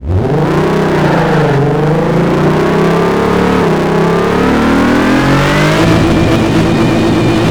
rev.wav